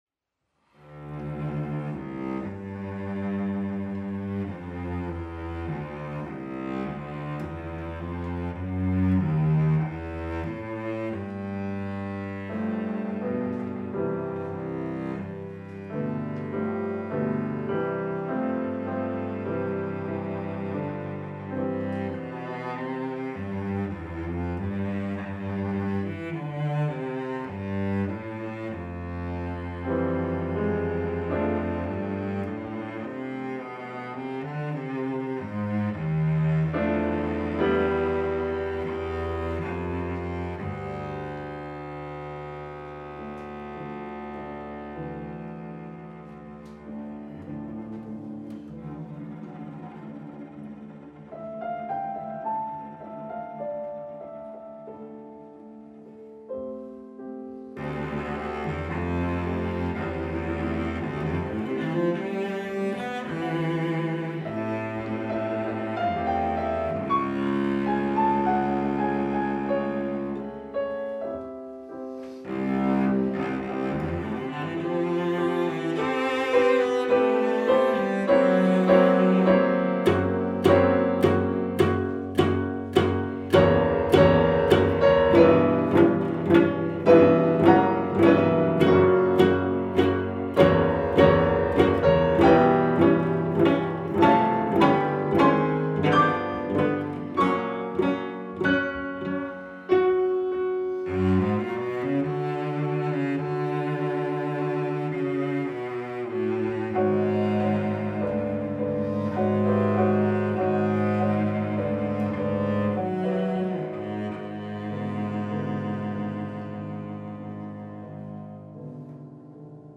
Venue: Bantry Library
Instrumentation: vc, pf Instrumentation Category:Duo
cello
piano